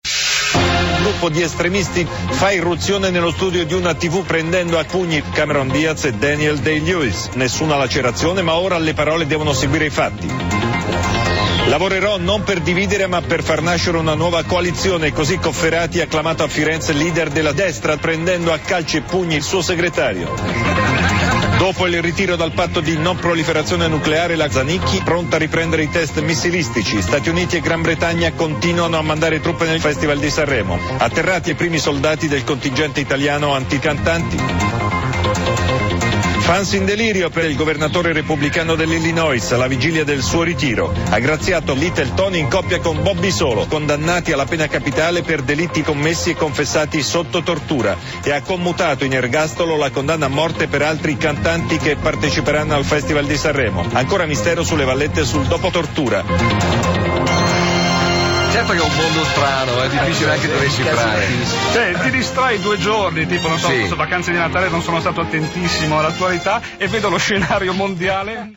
Alcuni titoli dei Tg rivisti dagli Elio e le storie tese all'interno del programma "Cordialmente" in onda su Radio Deejay.